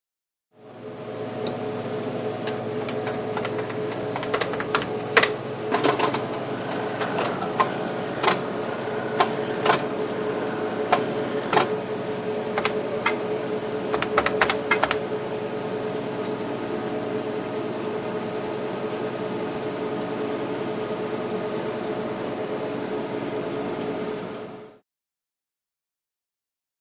Electrolyte Analyzer | Sneak On The Lot
Electrolyte Analysis; Electrolyte Analysis; Motor / Fan, Keypad Data Entry, Mechanical Stirring; Close Perspective. Hospital, Lab.